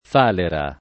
[ f # lera ]